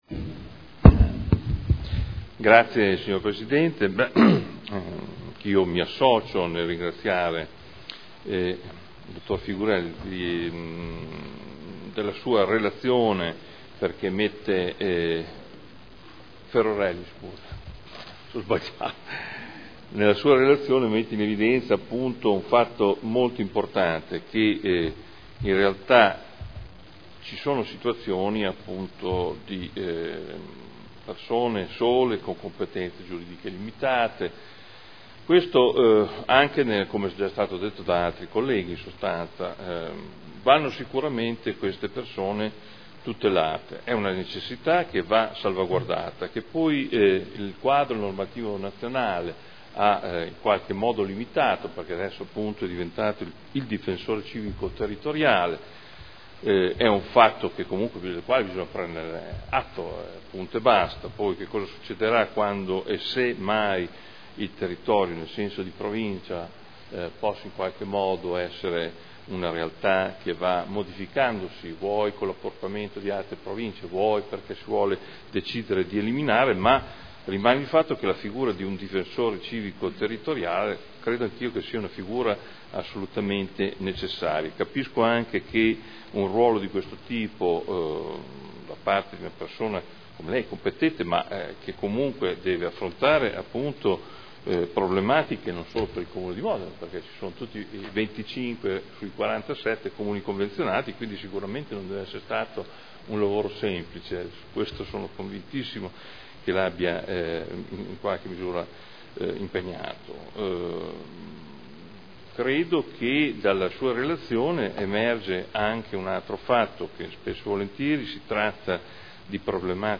Seduta del 11/02/2013 Dibattito. Relazione Difensore Civico su attività 2012